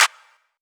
MB Clap (3).wav